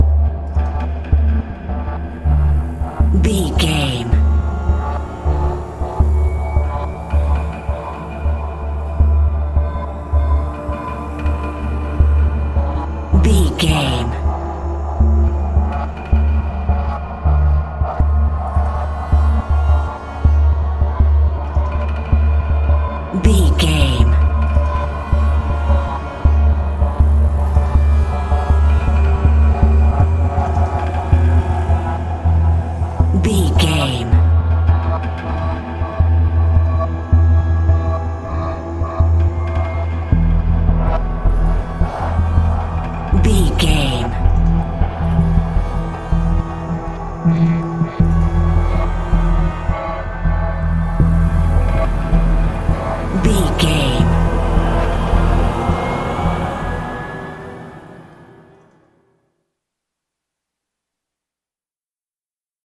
Thriller
Aeolian/Minor
E♭
Slow
synthesiser
tension
ominous
dark
suspense
haunting
creepy
spooky